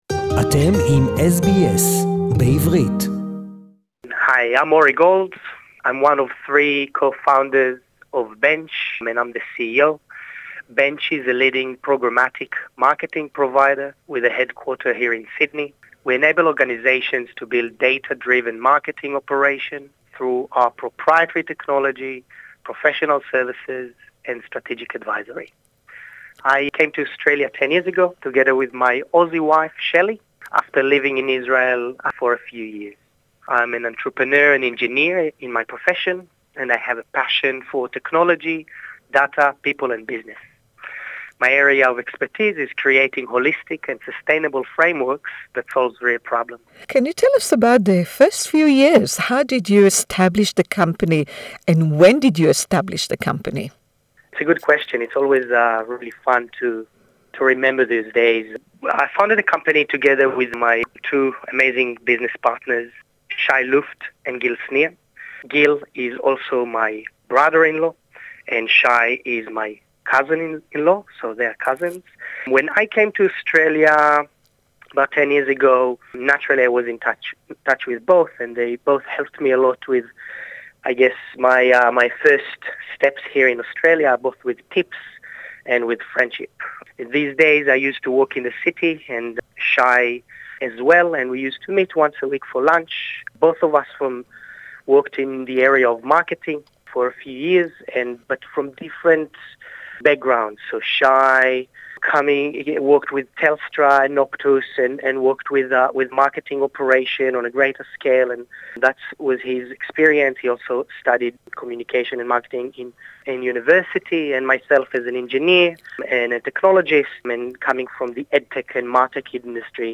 The Interview today is in English.